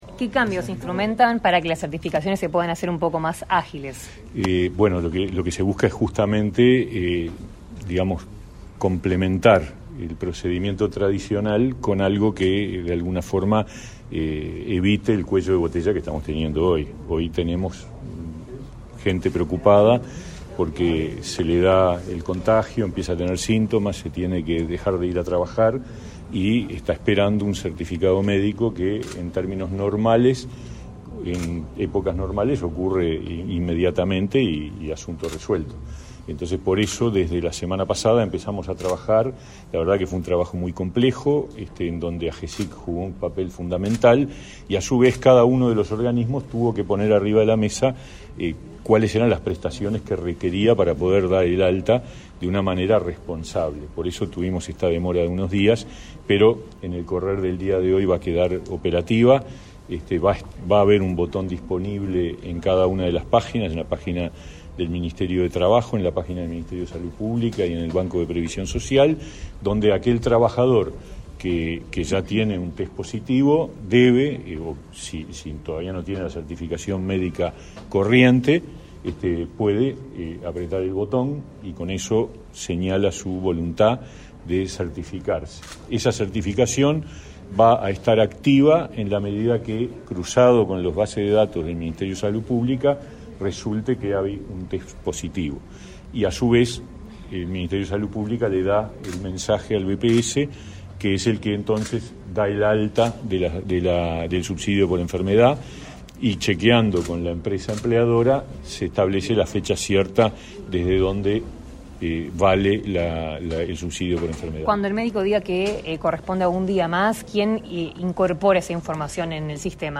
Declaraciones a la prensa del ministro de Trabajo y Seguridad Social, Pablo Mieres